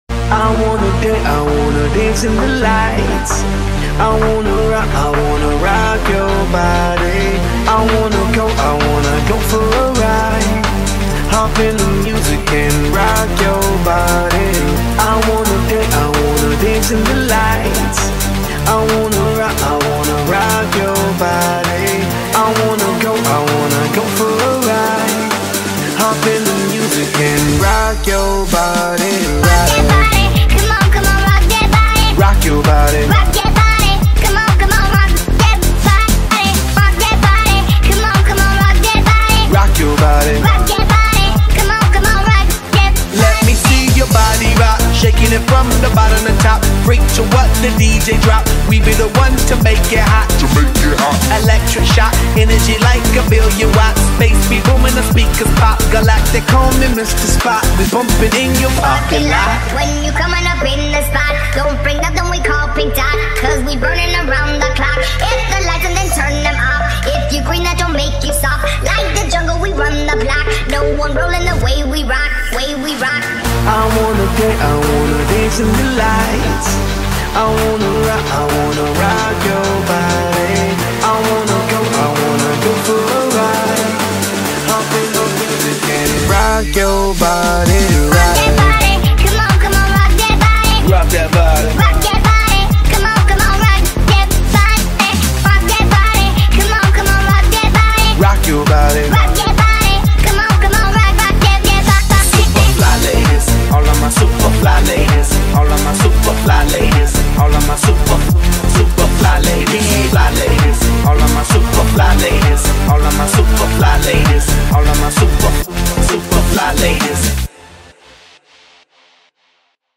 شاد
ماشینی